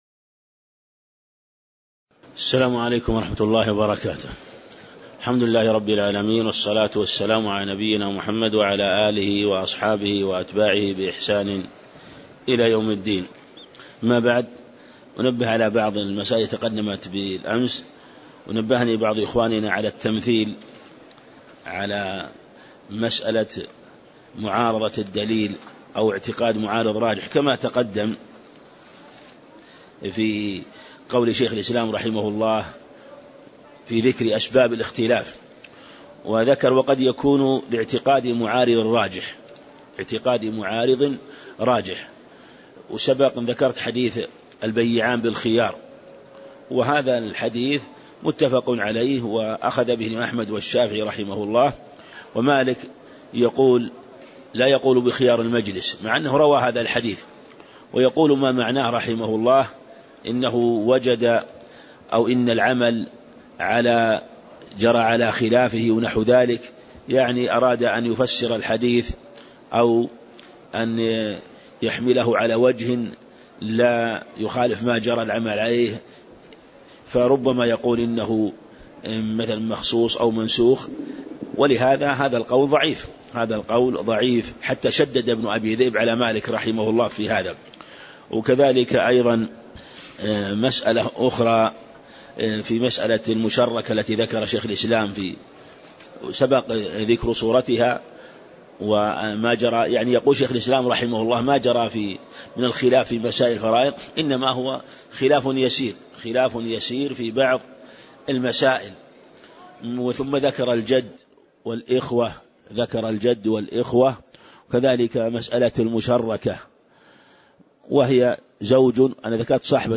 تاريخ النشر ١٨ شوال ١٤٣٨ هـ المكان: المسجد النبوي الشيخ